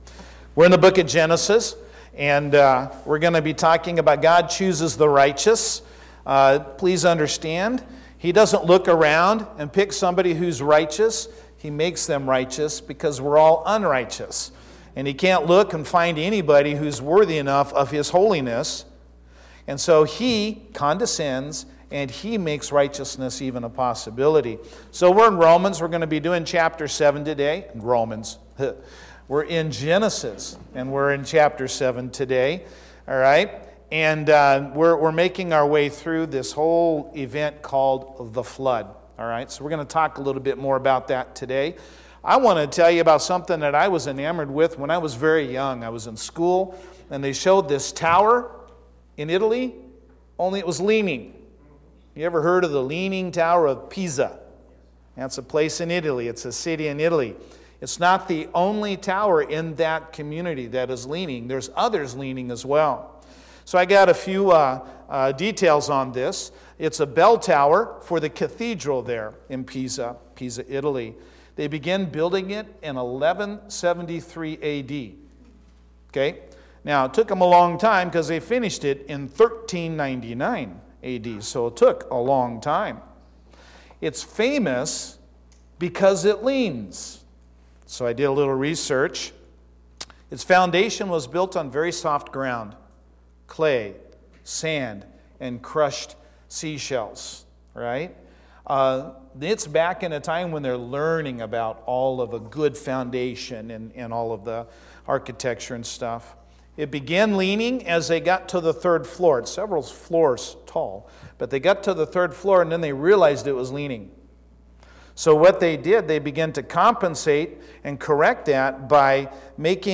3-22-20-Sermon.mp3